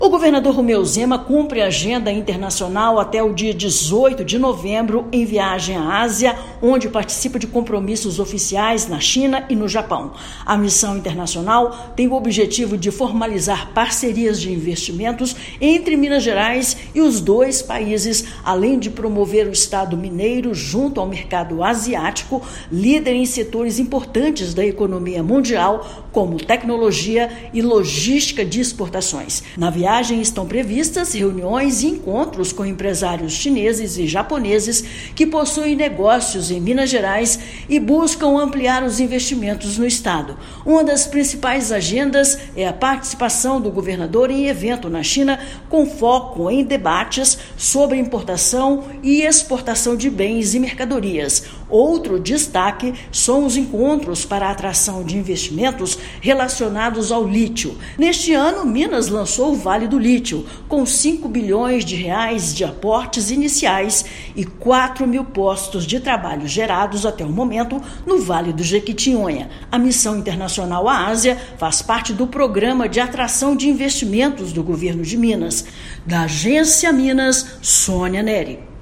[RÁDIO] Governo de Minas busca investimentos em missão internacional na China e no Japão
Governador tem agendas oficiais com autoridades dos governos chineses e japoneses, além de encontros com empresários do setor de tecnologia. Ouça matéria de rádio.